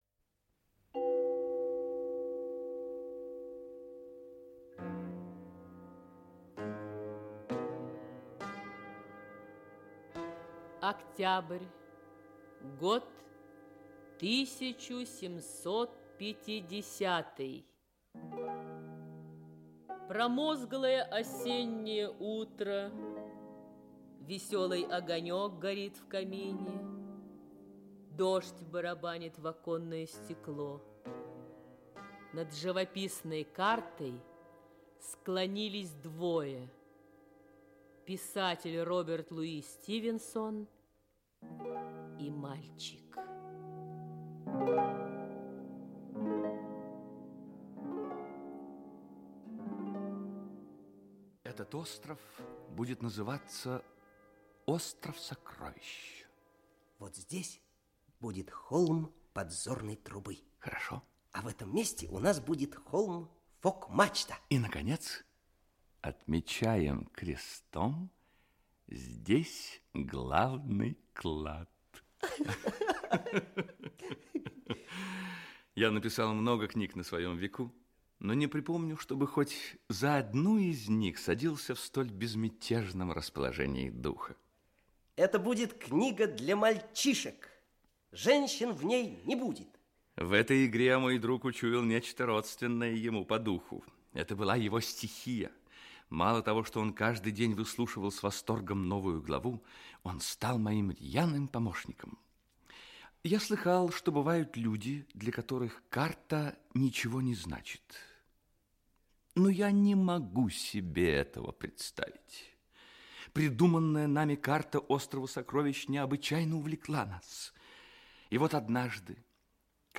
Аудиокнига Остров сокровищ (спектакль) | Библиотека аудиокниг
Aудиокнига Остров сокровищ (спектакль) Автор Роберт Льюис Стивенсон Читает аудиокнигу Георгий Вицин.